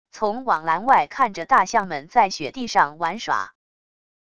从网栏外看着大象们在雪地上玩耍wav音频